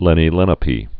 (lĕnē lĕnə-pē)